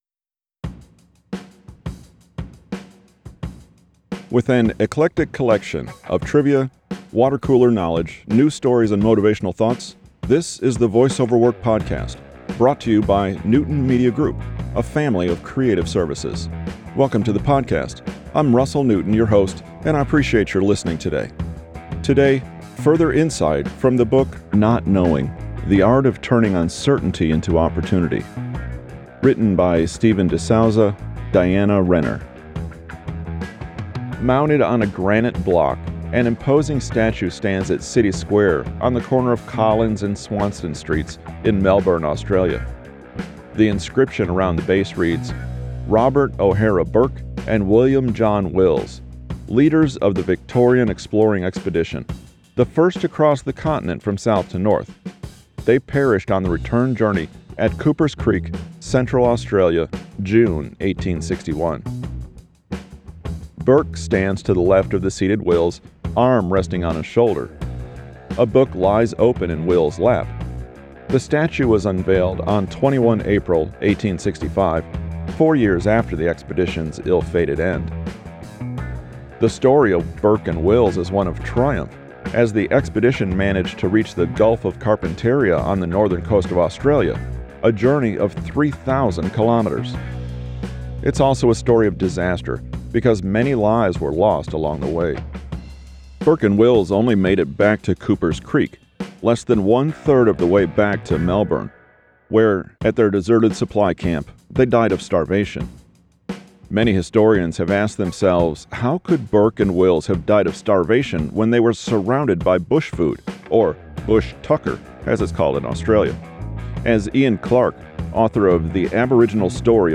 The Australian Journey of Burke and Wills – Voice over Work